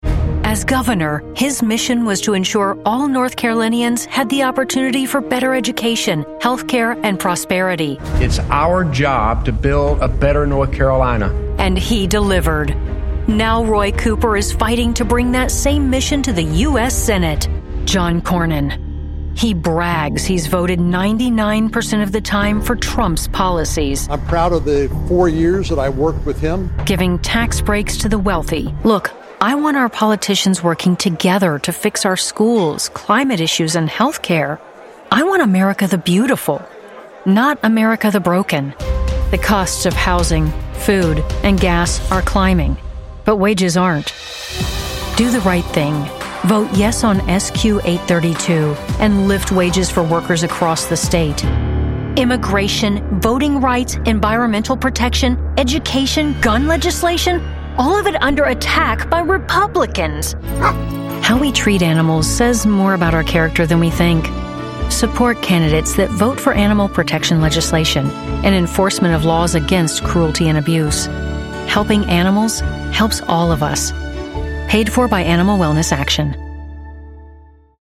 delivering a warm, articulate American English voice ideal for corporate narration, e-learning, political spots, and commercials
Political Ads
WhisperRoom Isolation Booth, Roswell Pro Audio RA-VO mic, Sound Devices USBPre2 interface, Mac computers, Source Connect